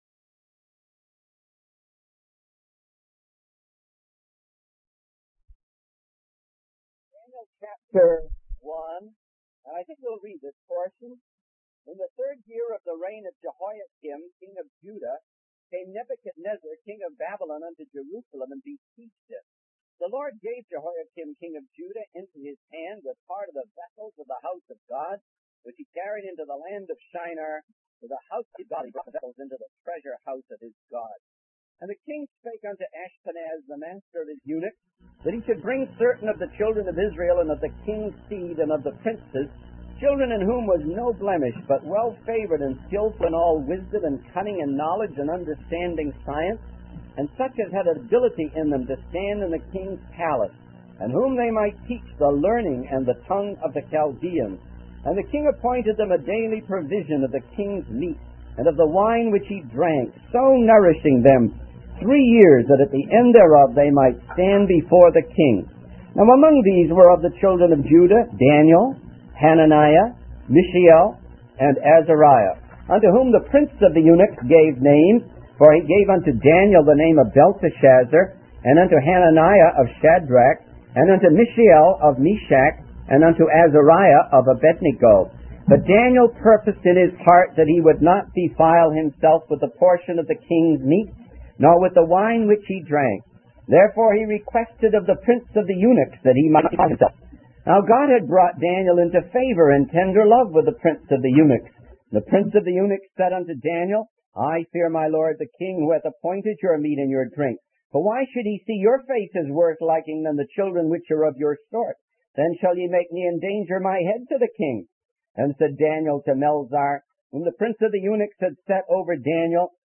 In this sermon, the speaker discusses the dark days in Israel described in the first two verses of the Bible.